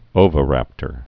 (ōvə-răptər)